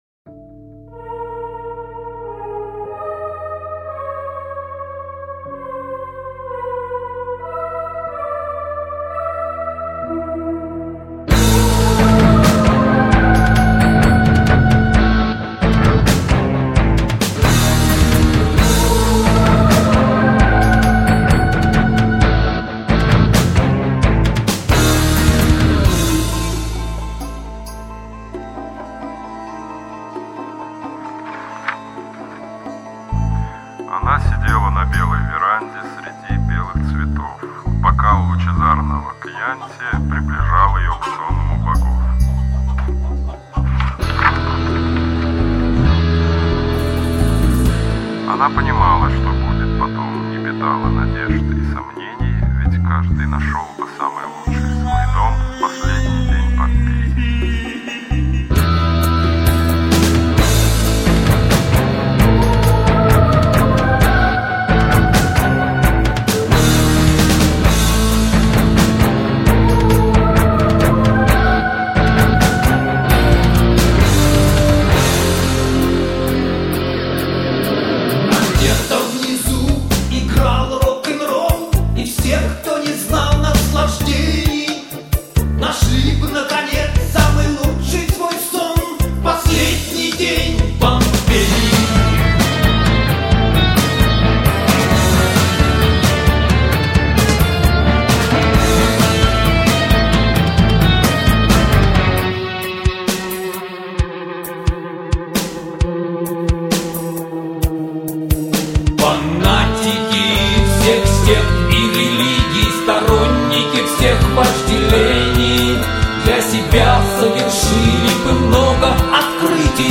программирование, клавишные, гитара.
вокал, программирование ударных.
речитатив.